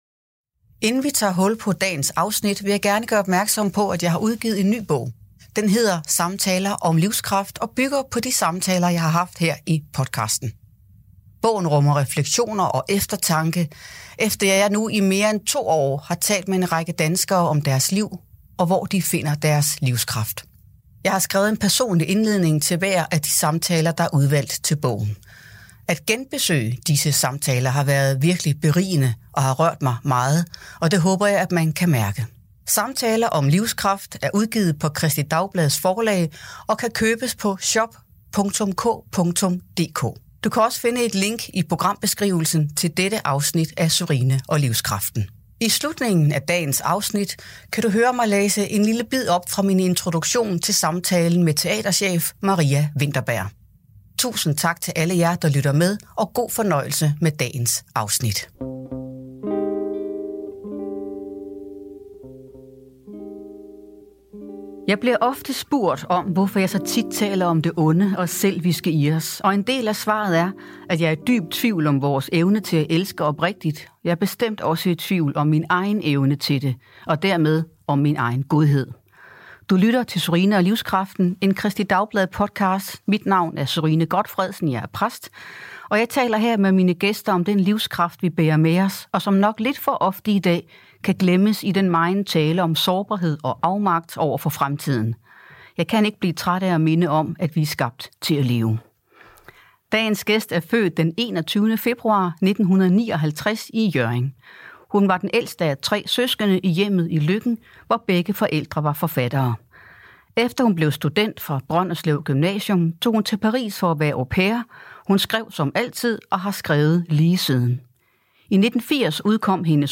I denne podcastserie taler hun i hvert afsnit med en gæst, der deler en personlig fortælling om at finde livskraften i et definerende øjeblik.